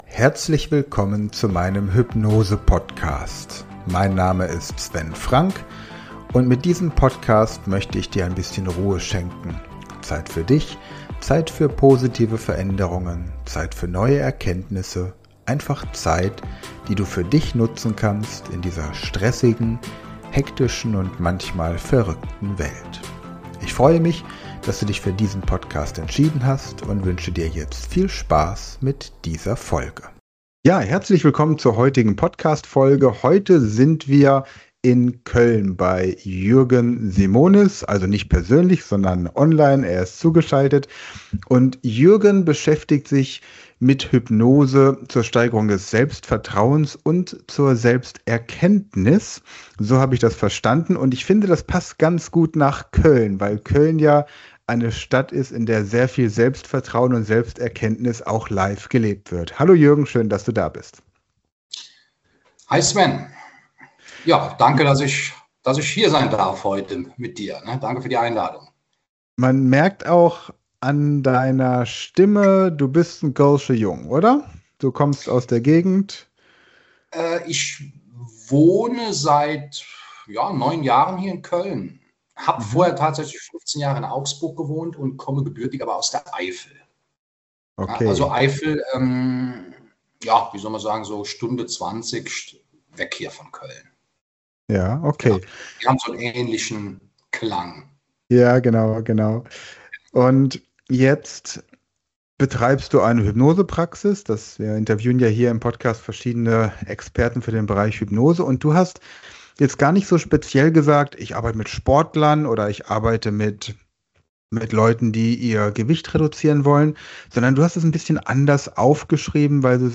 Wie er seinen Klienten hilft, ähnliche Situationen erfolgreich zu meistern, das erklärt er in unserem Interview.